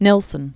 (nĭlsən), Birgit 1918-2005.